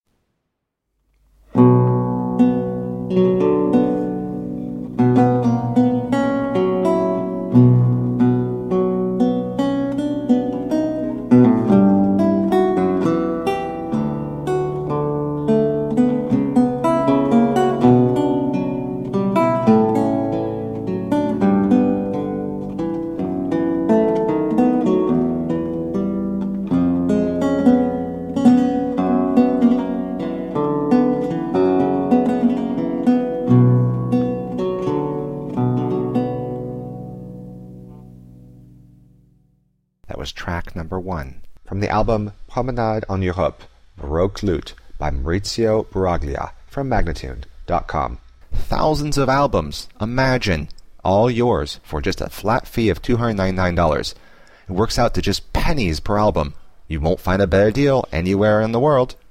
A marvelous classical spiral of lute sounds.
Classical, Baroque, Instrumental
Lute